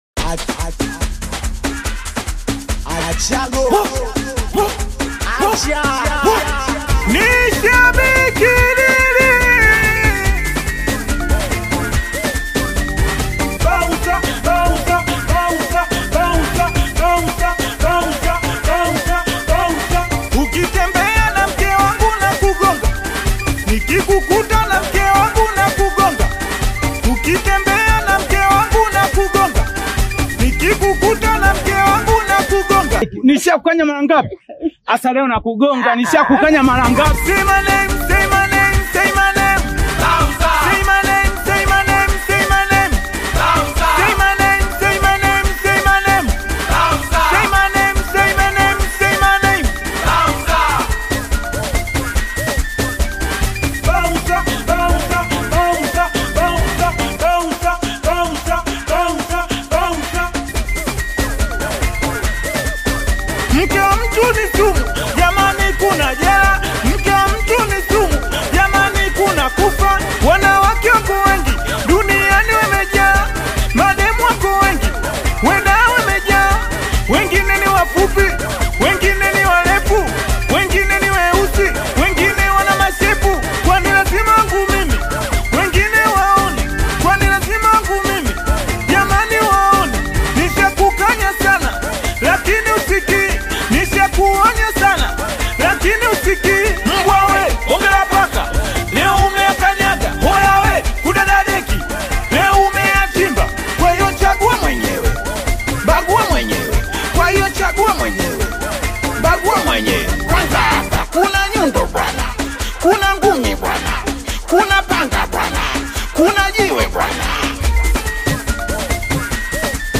Known for his energetic delivery and unique voice